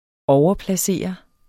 Udtale [ ˈɒwʌplaˌseˀʌ ]